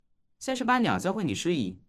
底部模型推理
如果你仔细听，这些原音频虽然未在底部模型中，但是输出的音色却是非常的相似，在后续的推理模型训练中，只需要使用我们的底模+说话人（可能少部分的说话）+情绪语句，即可得到一个很好的推理模型。